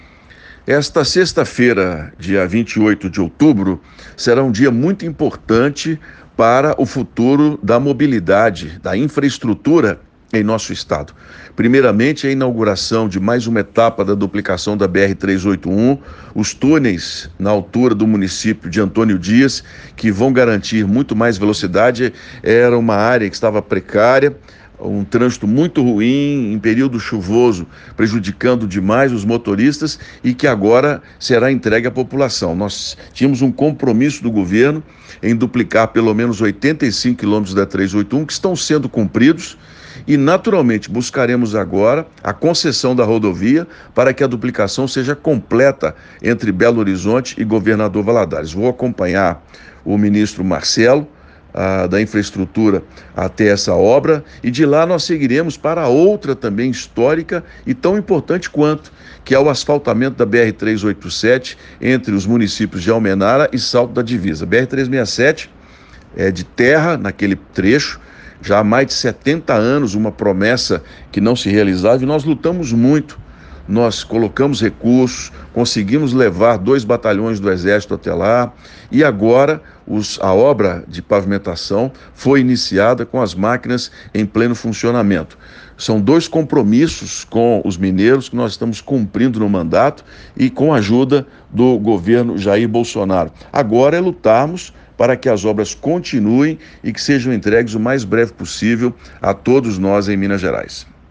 Confira o pronunciamento do senador: